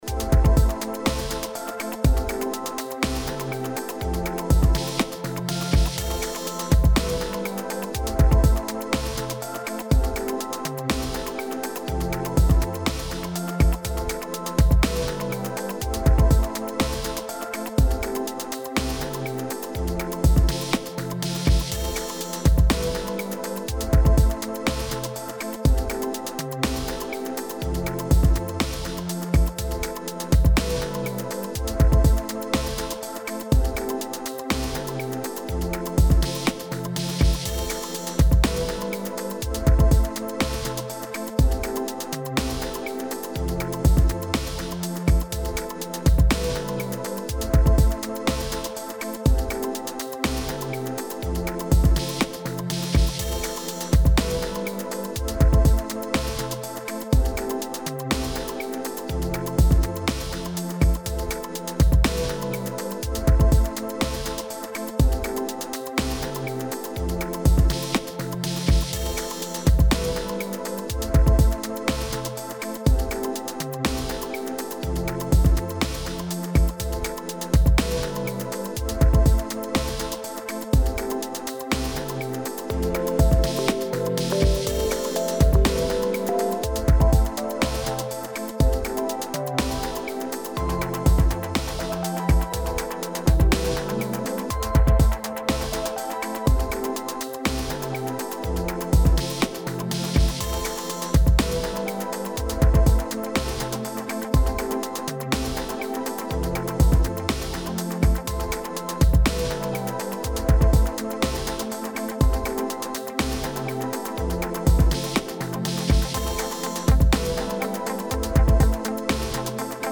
EDMロング激しい